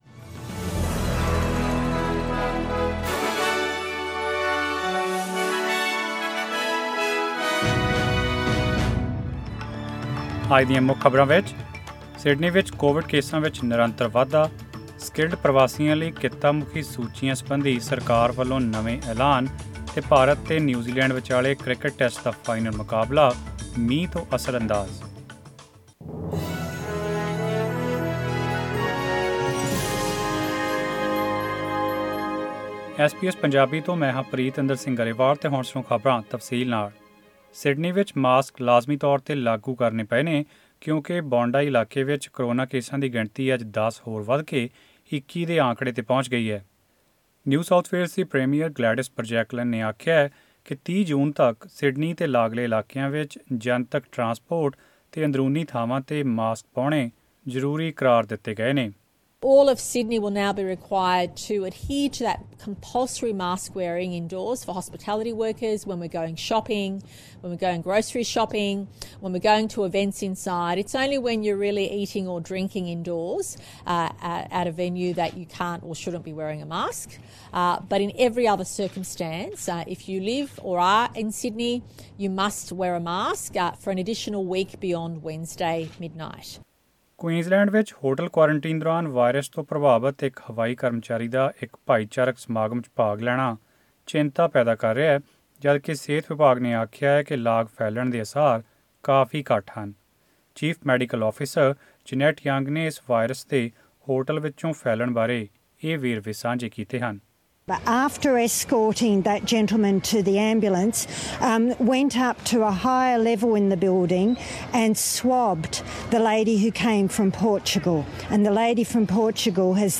Click on the player at the top of the page to listen to the news bulletin in Punjabi.